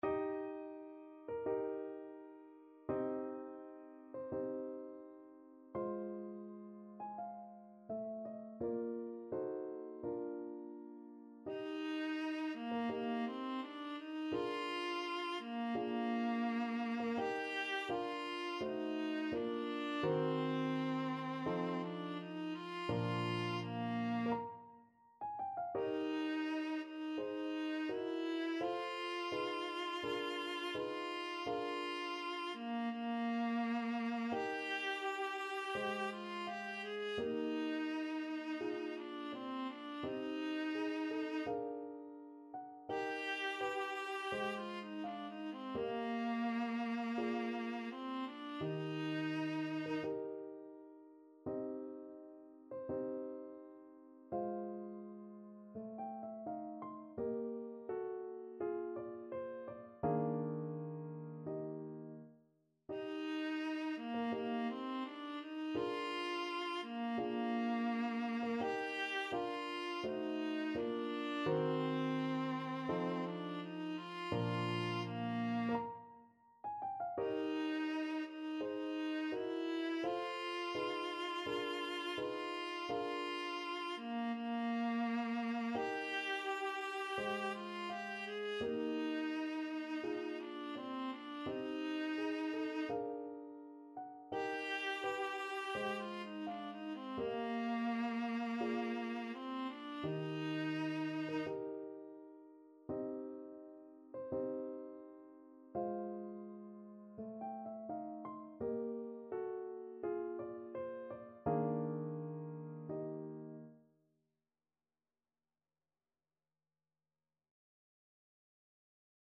Viola version
2/4 (View more 2/4 Music)
~ = 42 Sehr langsam
Classical (View more Classical Viola Music)